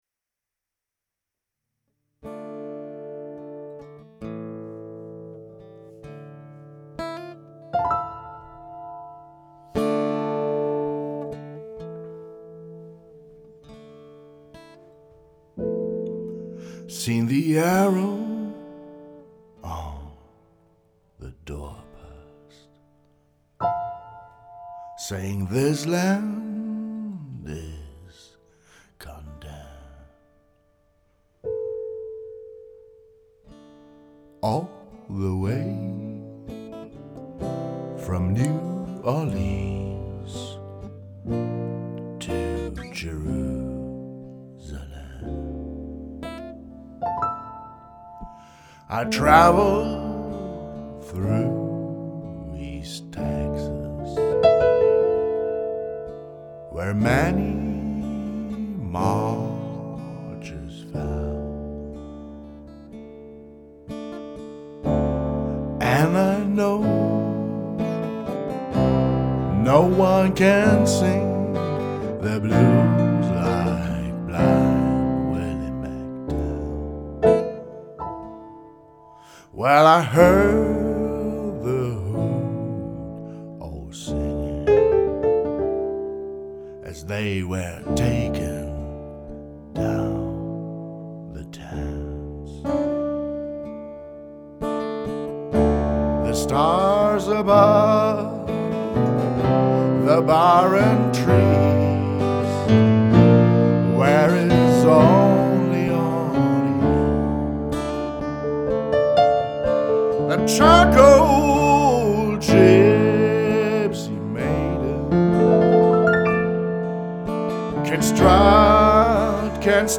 live at the 39108